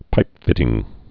(pīpfĭtĭng)